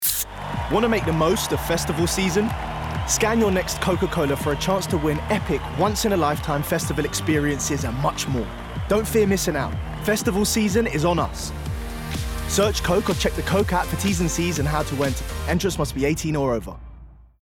STYLE: Audiobook
20/30's London, Contemporary/Friendly/Warm